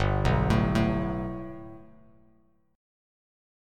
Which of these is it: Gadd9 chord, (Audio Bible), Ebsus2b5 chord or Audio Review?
Gadd9 chord